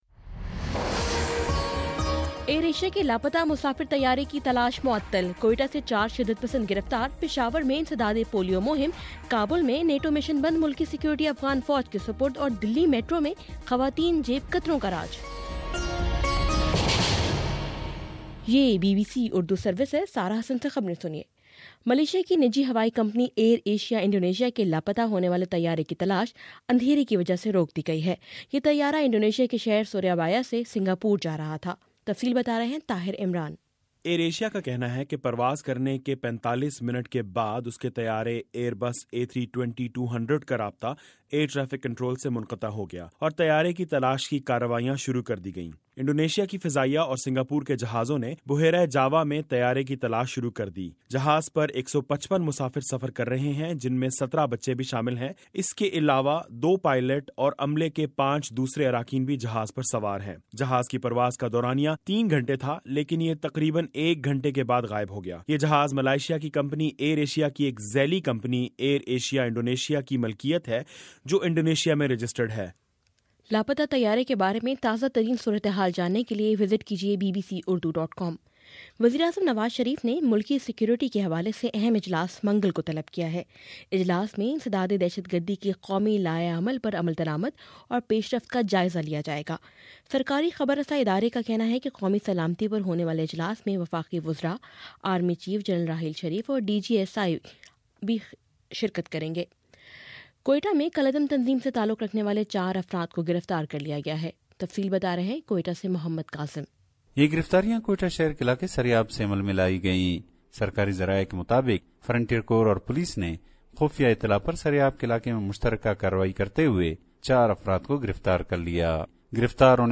دسمبر28: شام سات بجے کا نیوز بُلیٹن